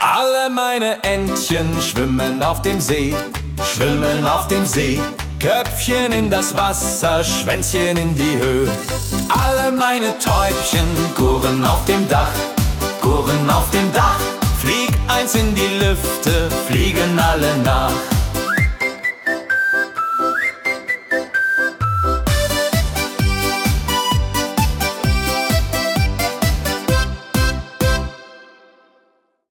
Alle meine Entchen – Volksmusik [Deutscher Schlager, accordion, cheerful, upbeat, sing-along, traditional German pop, festive]